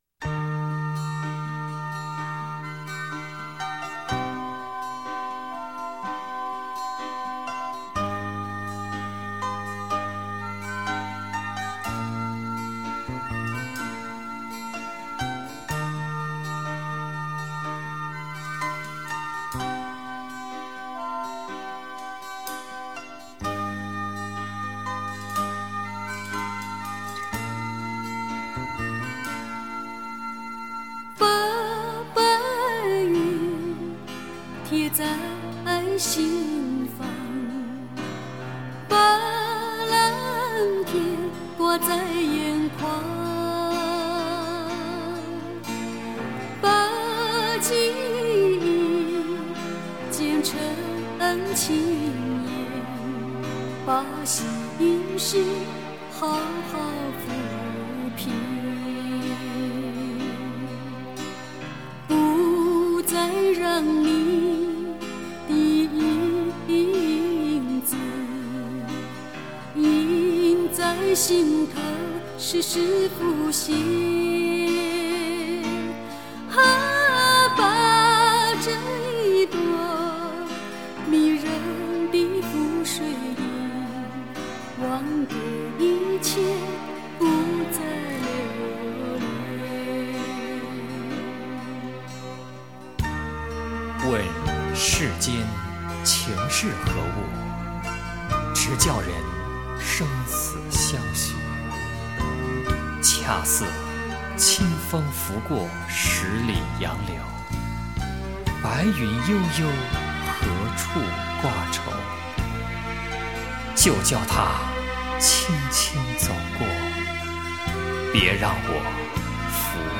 柔情名曲 珍爱一生
她的歌，用温柔浅唱 她的歌，在永恒转航